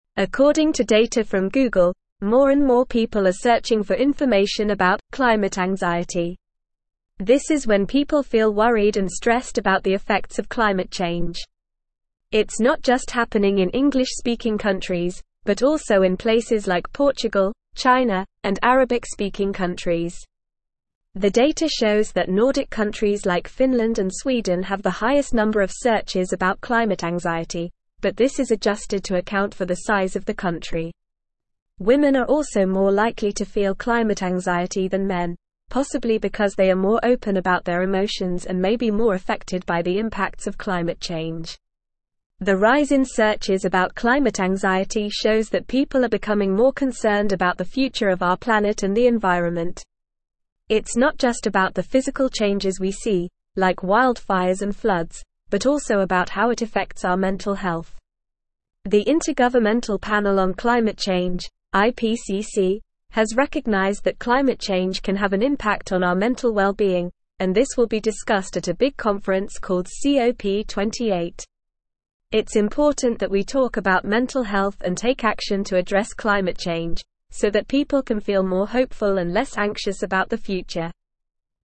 Normal
English-Newsroom-Upper-Intermediate-NORMAL-Reading-Rising-Search-Queries-Show-Womens-Climate-Anxiety-Increase.mp3